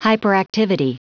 Prononciation du mot hyperactivity en anglais (fichier audio)
Prononciation du mot : hyperactivity